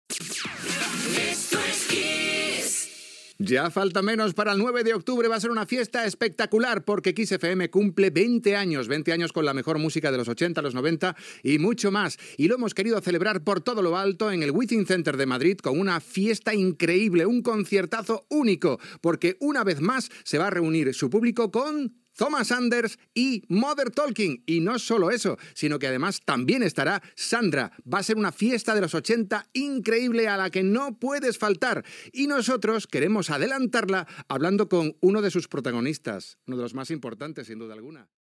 Indicatiu de la ràdio, anunci de la festa dels 20 anys de Kiss FM, a Madrid